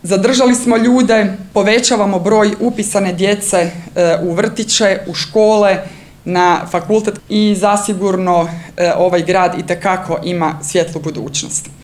U utorak, 04. ožujka 2025. godine, u Petrinji je svečano otvorena novoobnovljena zgrada Učiteljskog fakulteta – Odsjek u Petrinji.
Gradonačelnica Petrinje Magdalena Komes istaknula je kako je „učiteljstvo baza u ovom gradu, i puno toga se razvilo na račun prosvjete i svega što se nadovezuje na to”